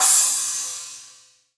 add drum sound samples from scratch 2.0
Crash(2)_22k.wav